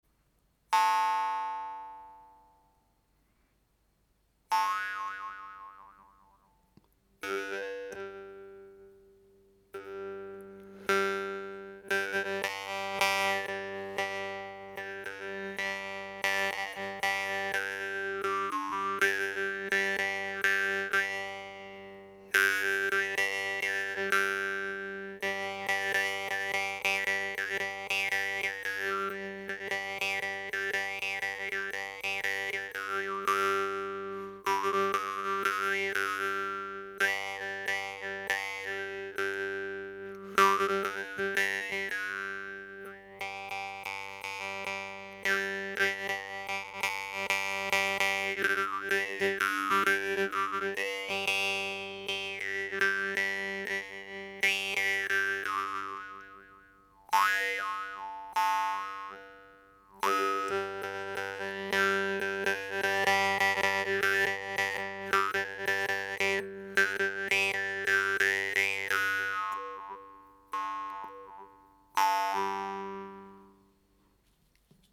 Guimbarde norvégienne de type SETESDAL, avec des formes rondes. Elles ont une languette légèrement plus rigide, sont légèrement plus longues et ont un volume sonore un peu plus important que les Munnharpe TELEMARK, ce qui les rend particulièrement adaptées au jeu mélodique percutant.